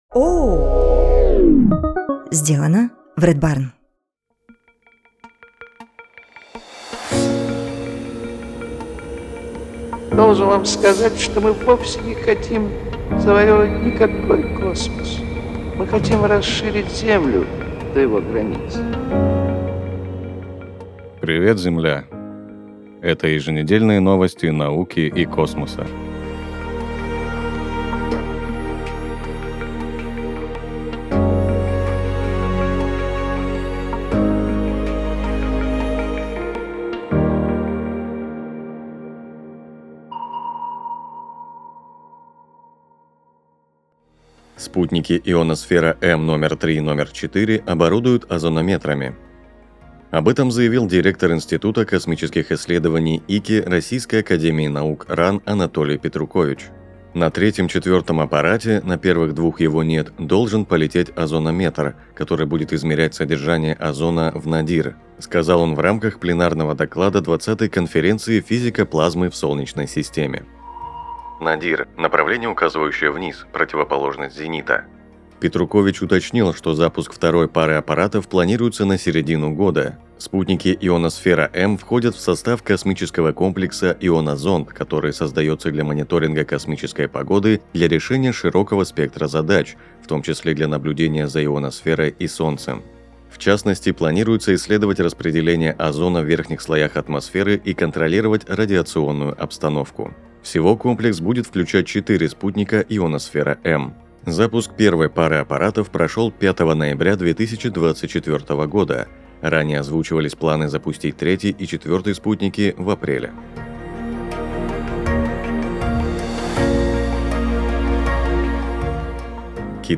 Ведет выпуск